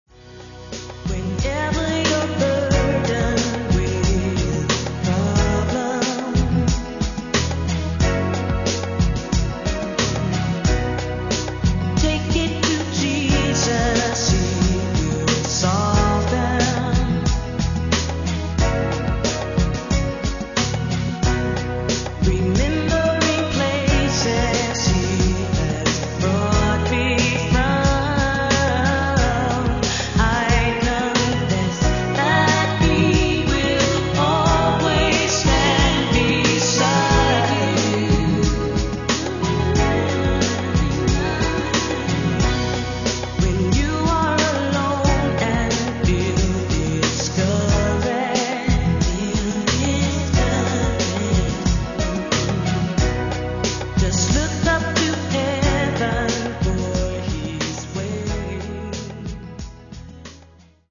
dance/electronic
RnB